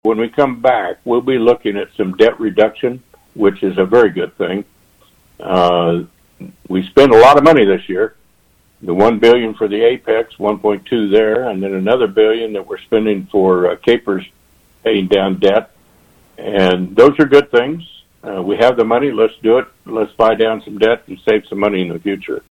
51st District Representative Ron Highland, a guest on KVOE’s Morning Show this week, says part of the conversation will include which food categories are eligible for reducing or eliminating the tax.